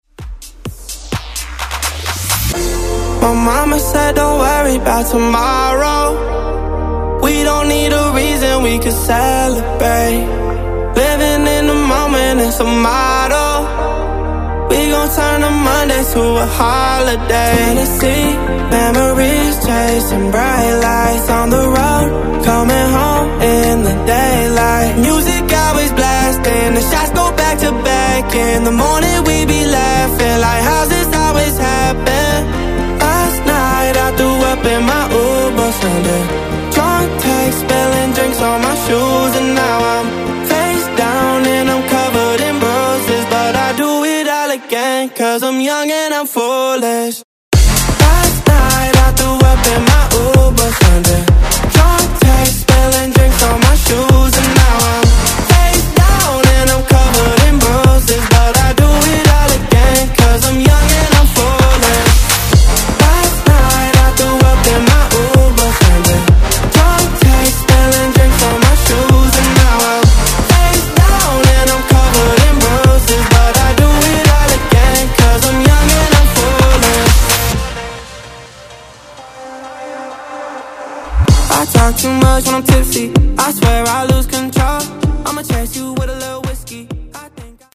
Genres: DANCE , RE-DRUM
Clean BPM: 125 Time